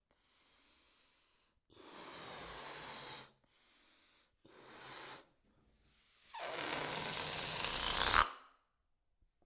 气球 " globo05
描述：球囊处理
标签： 气球
声道立体声